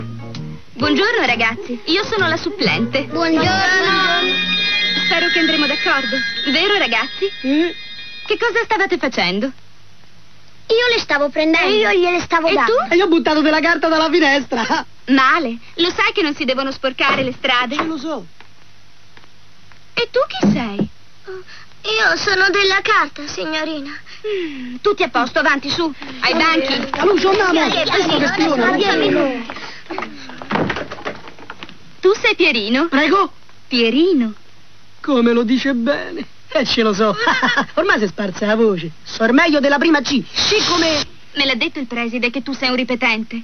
nel film "Pierino colpisce ancora", in cui doppia Michela Miti.